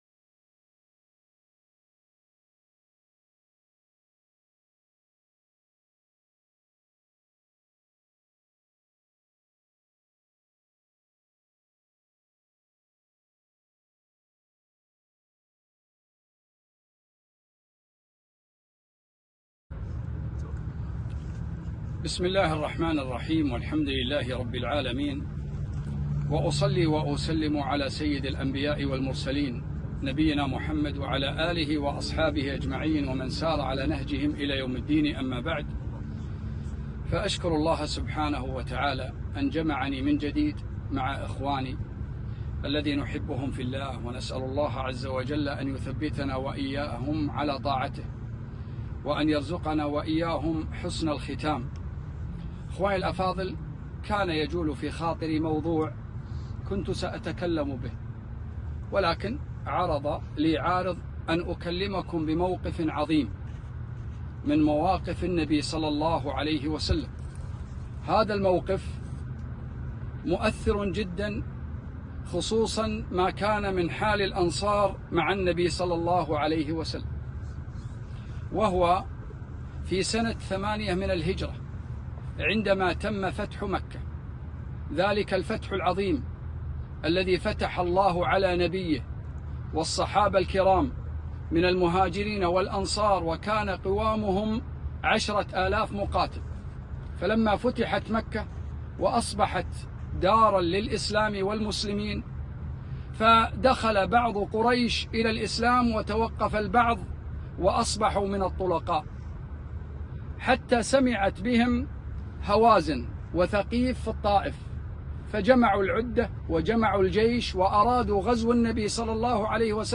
كلمة - فاصبروا حتى تلقوني على الحوض